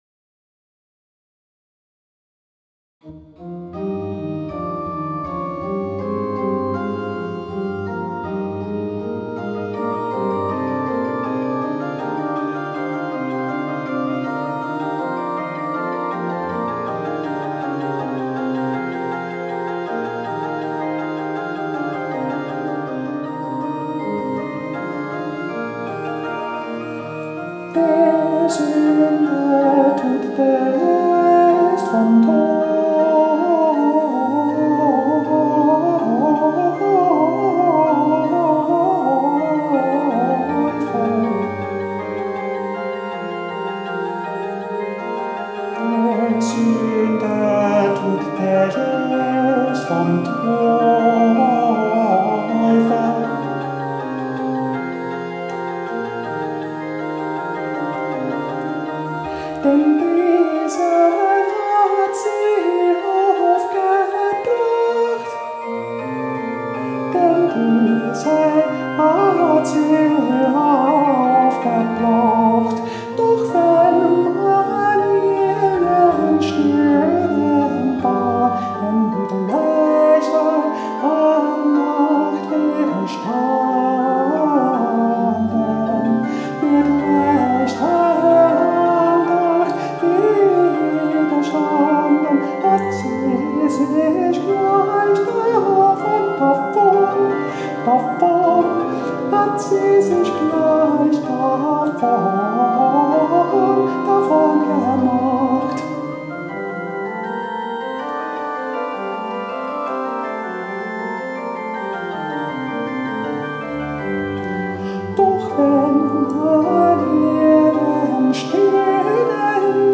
The counterpoint is very complex, with long passages of sixteenth notes that wind around implied simpler melodies.
recording of the full version, with me singing and the computer playing all the notes that Bach wrote for the instrumental parts.